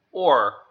o    or vowels sound like the or in «for»
how to pronounce or